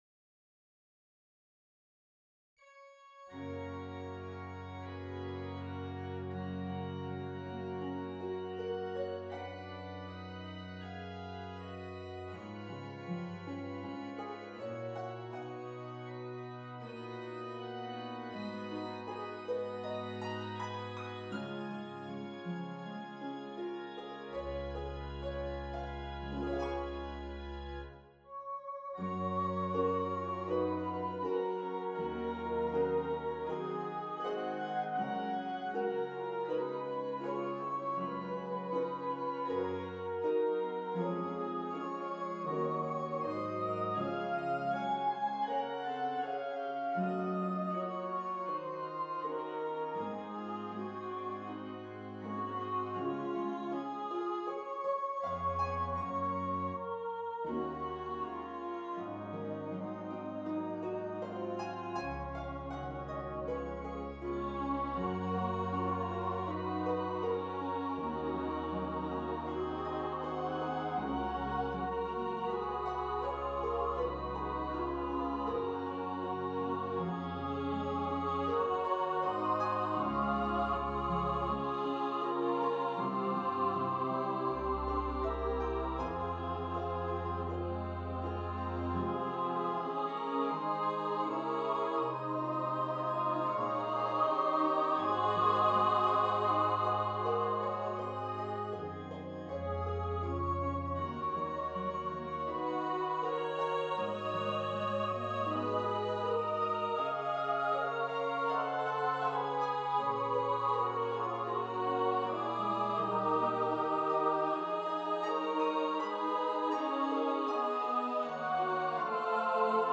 Voices: Soprano solo & Women's Chorus (S1,S2,A1,A2) Instrumentation: Harp & Organ
Note Performer mp3 Download/Play Audio